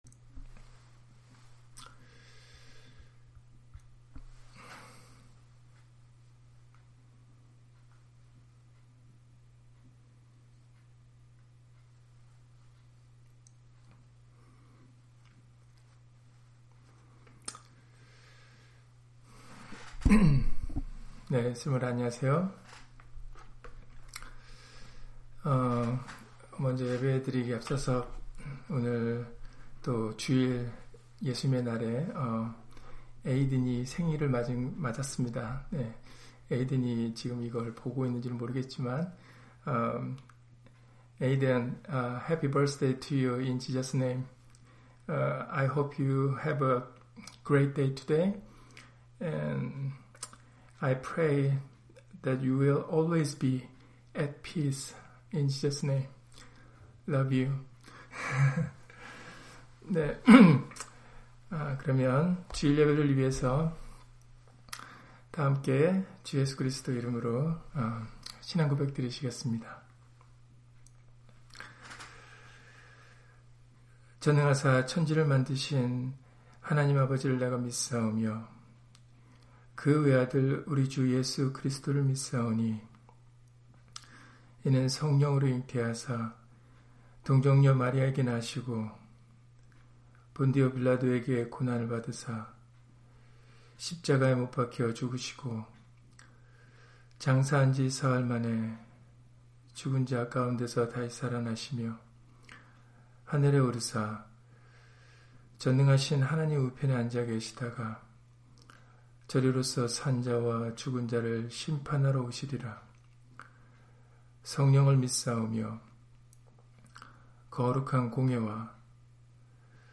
다니엘 3장 28-30절 [의뢰 = 굳게 믿고 의지함] - 주일/수요예배 설교 - 주 예수 그리스도 이름 예배당